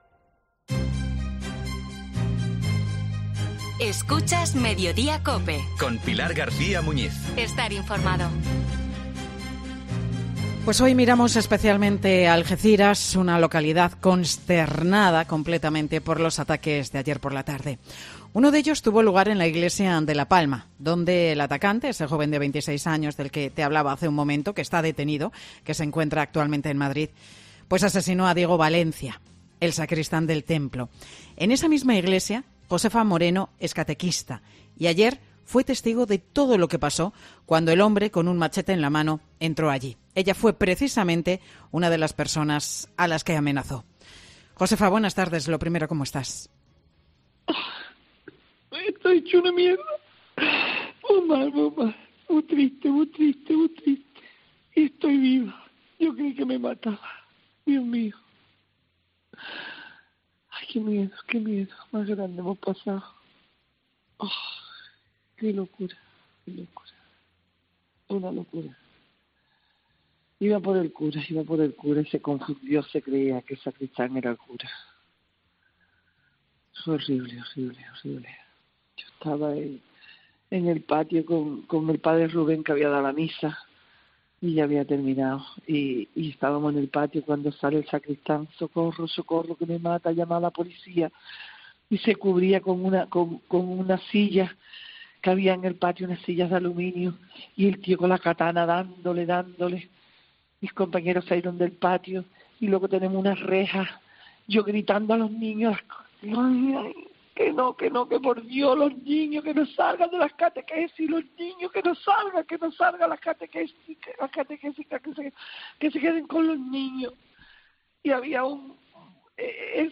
Entre sollozos angustiosos